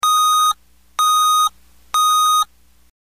Cicalino Buzzer Bitonale Retromarcia Ecco 97dB 12V/24V
Cicalino/Buzzer Bitonale Retromarcia per Autocarri / Mezzi da Cantiere
Tono: Bitonale Decibel: 97dB (a 120cm)